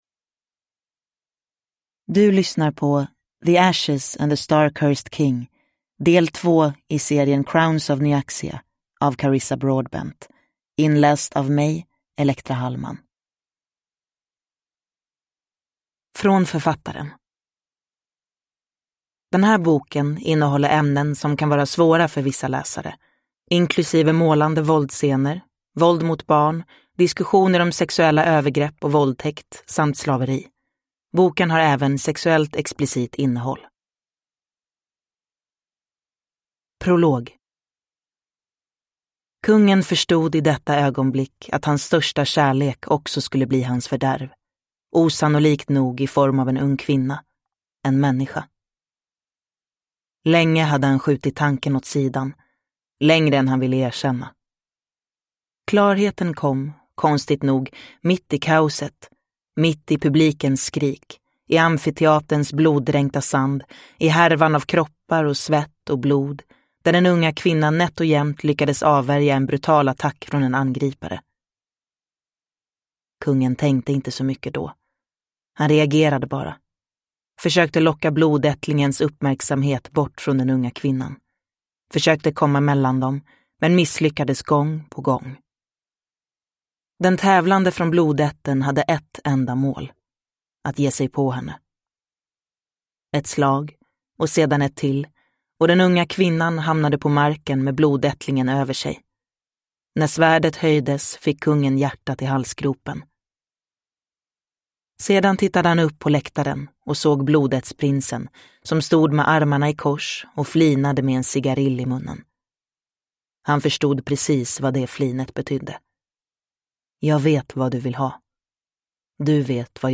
The Ashes and the Star Cursed King (Svensk utgåva) – Ljudbok